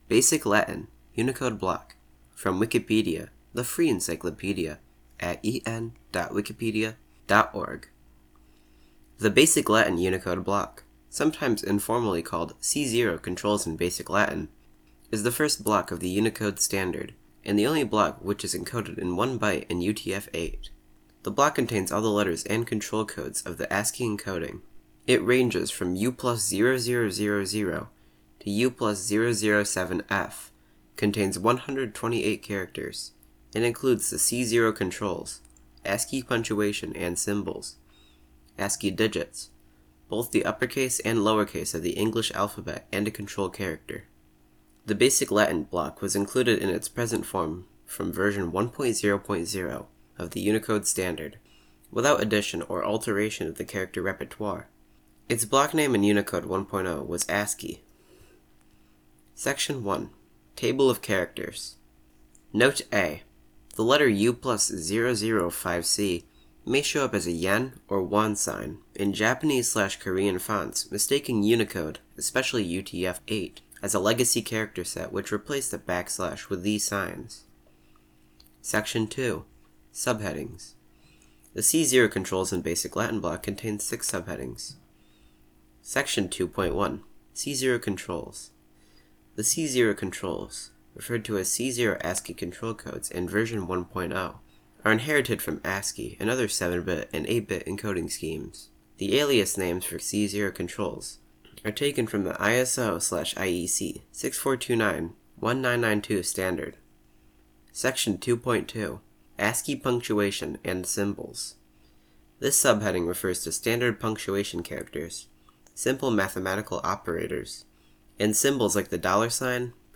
This is a spoken word version of the Wikipedia article: Basic Latin (Unicode block)
Dialect/Accent InfoField US English Gender of the speaker InfoField Male
En-Basic_Latin_(Unicode_block)-article.ogg